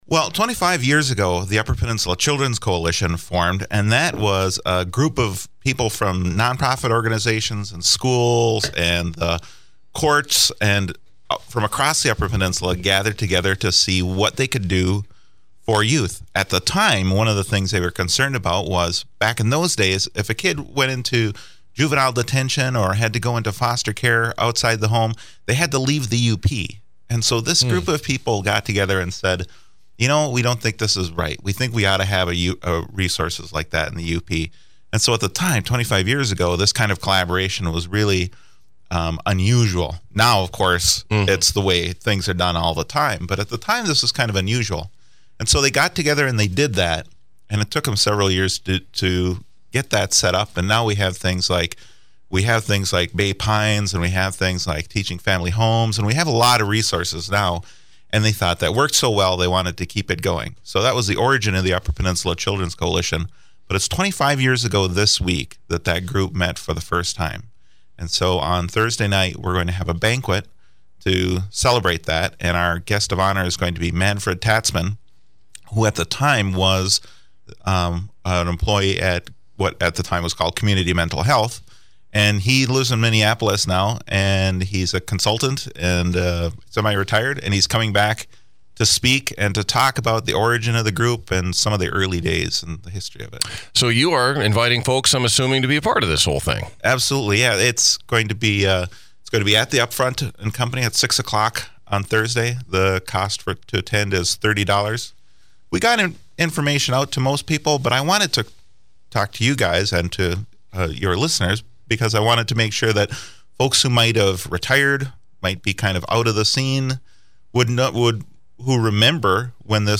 INTERVIEW:Marquette County Undersheriff Jack Schneider – Motorcycle Safety Awareness Month